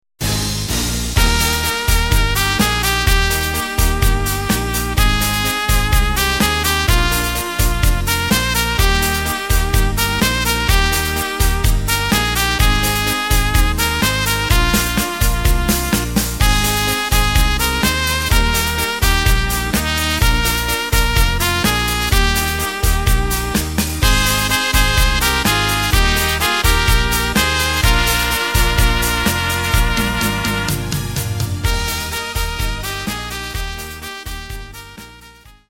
Takt:          4/4
Tempo:         126.00
Tonart:            Eb
Schlager Instrumental - TROMPETE - aus dem Jahr 2012!